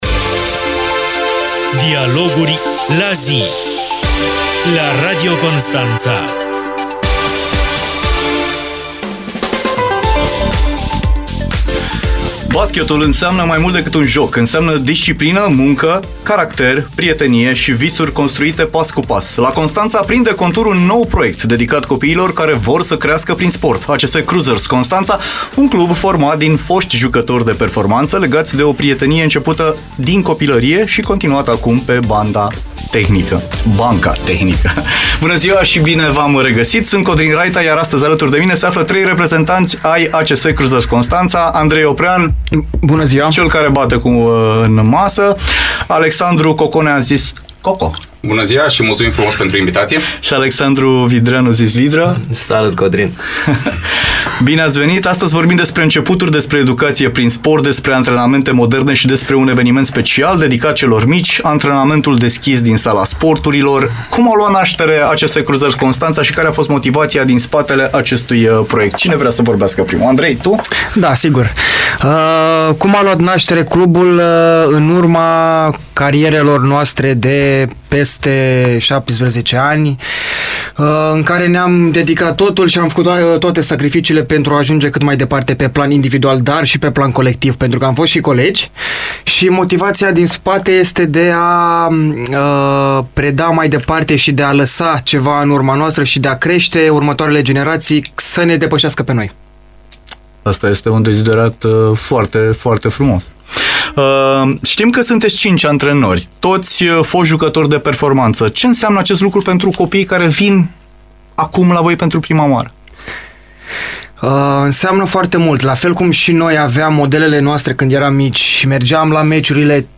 DIALOGURI LA ZI | Reprezentanți ai ACS Cruisers Constanța, invitați în emisiunea de astăzi - Știri Constanța - Radio Constanța - Știri Tulcea